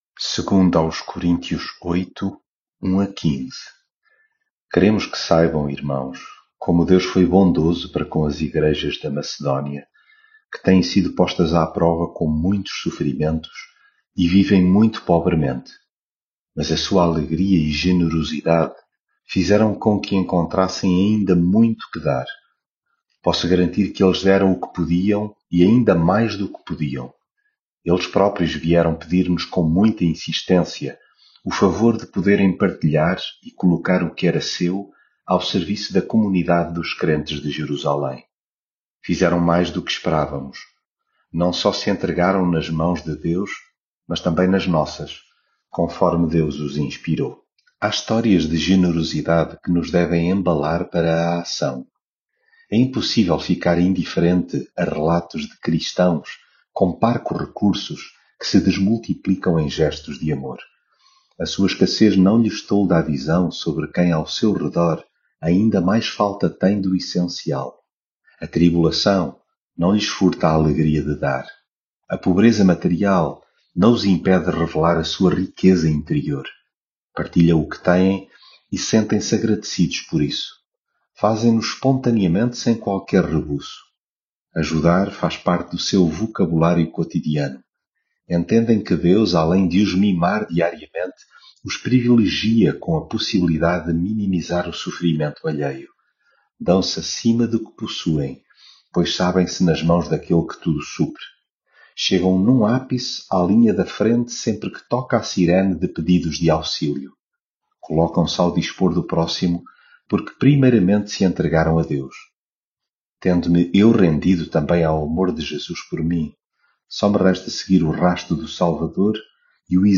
leitura bíblica
devocional